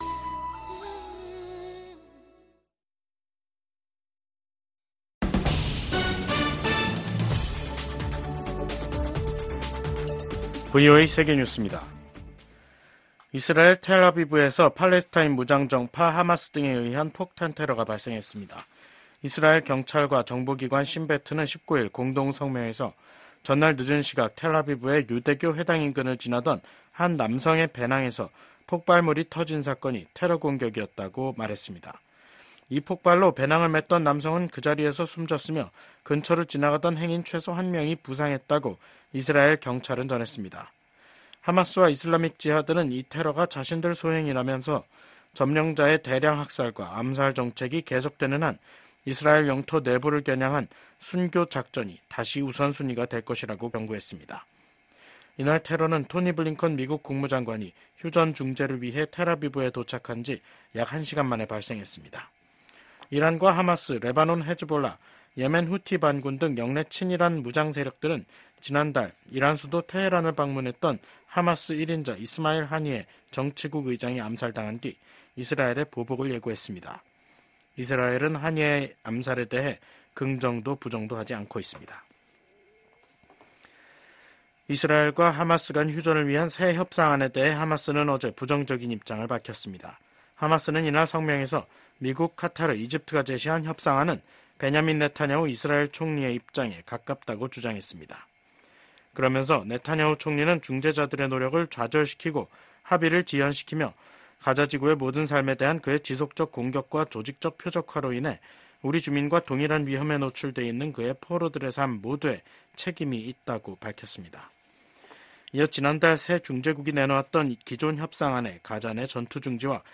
VOA 한국어 간판 뉴스 프로그램 '뉴스 투데이', 2024년 8월 19일 3부 방송입니다. 미국과 한국, 일본이 캠프 데이비드 정상회의 1주년을 맞아 공동성명을 발표했습니다. 북한이 올해 말부터 제한적으로 외국인 관광을 재개할 것으로 알려진 가운데 미국은 자국민 방북을 절대 불허한다는 방침을 거듭 확인했습니다. 미국 정부는 윤석열 한국 대통령이 발표한 ‘8.15 통일 독트린’에 대한 지지 입장을 밝혔습니다.